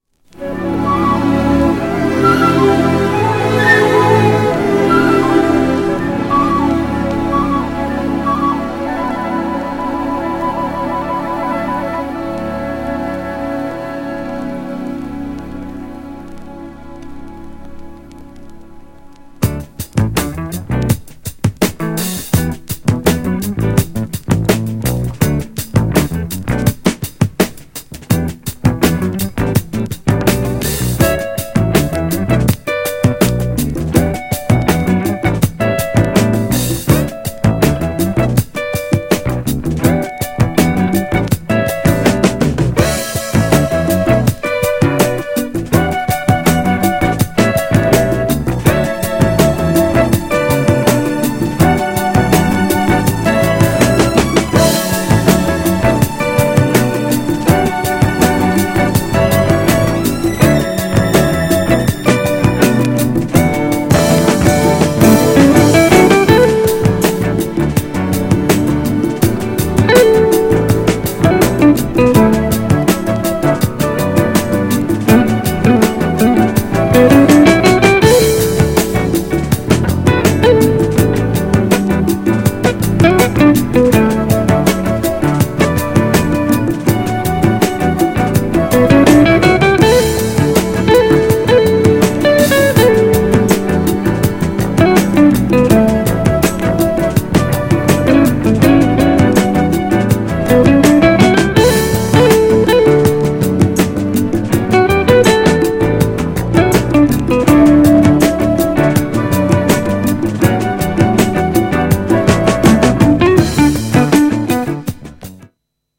GENRE Dance Classic
BPM 116〜120BPM